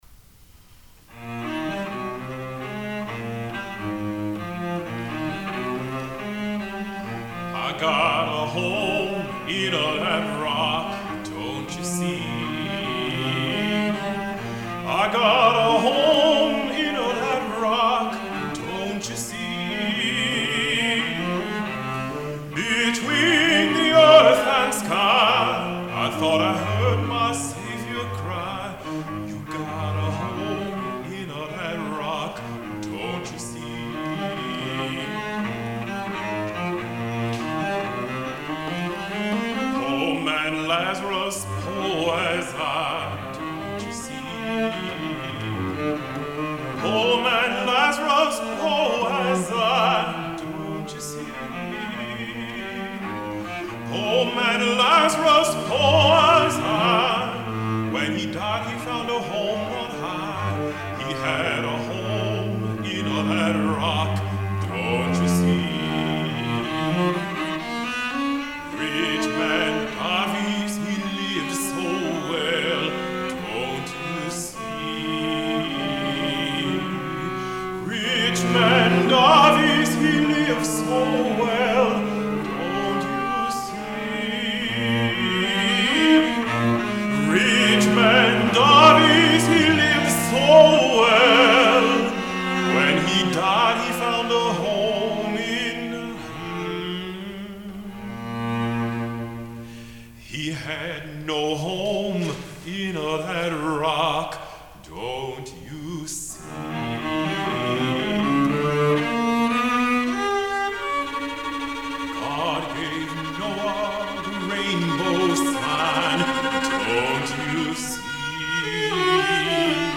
Voicing: Medium Voice and Piano